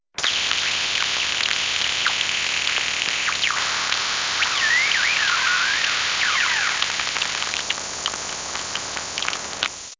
Cringe Sound Effect
A low, grating, and utterly uncoherent sound effect that is meant to be heard when someone is truly cringing. It's so weak, it's barely audible.